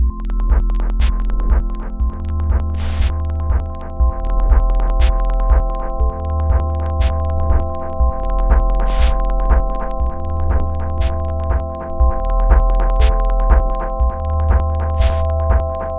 new chatgpt 4o sound generation is fun, text to python to sound.